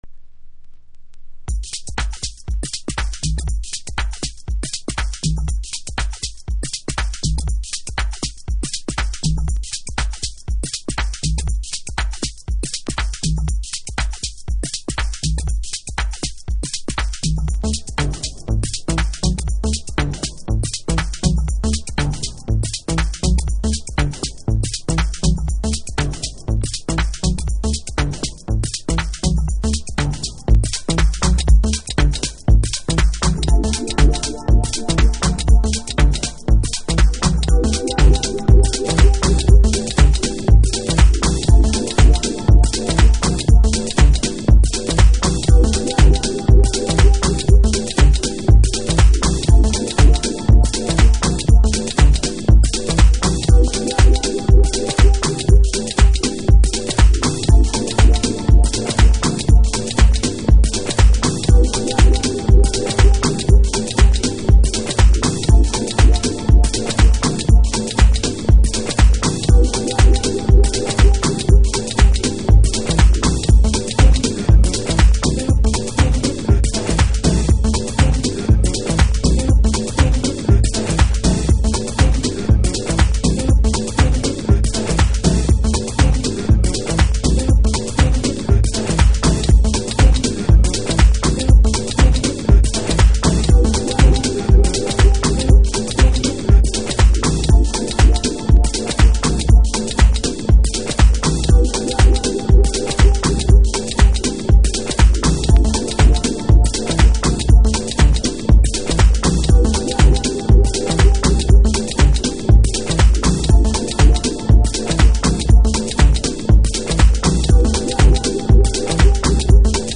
Detroit House / Techno
昨今珍しいヴォーカルサイドとダブサイドに別れた12inch。